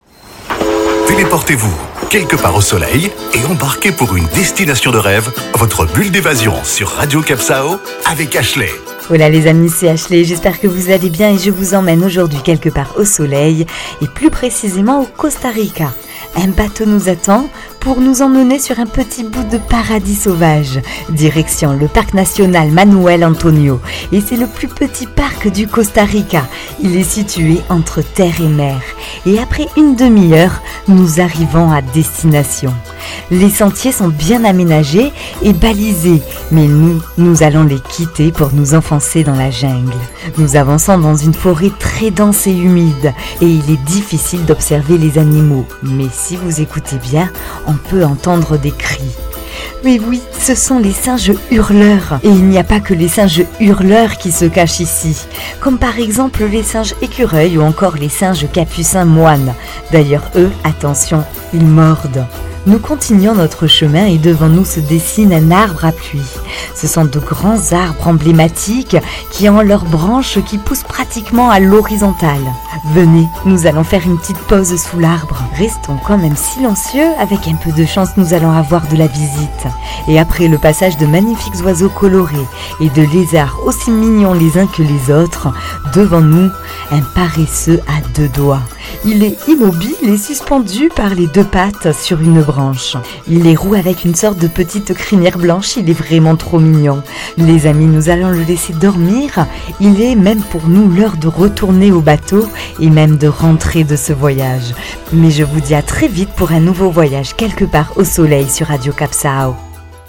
Carte postale sonore : venez faire de l'éco-tourisme dans l'un des parcs les plus riches et impressionnants du monde. Orné de multiples plages de sable blanc, il contient une grande biodiversité terrestre et marine et de très beaux récifs coralliens.